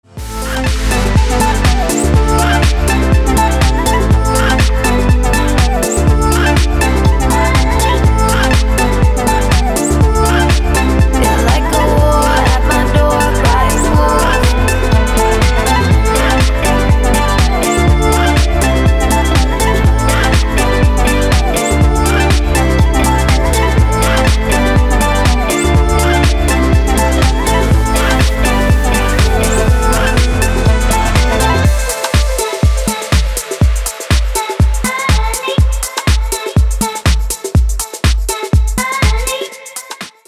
• Качество: 320, Stereo
женский вокал
deep house
dance
Electronic
EDM
спокойные
забавный голос
Vocal House
Chill House